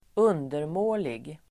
Uttal: [²'un:dermå:lig]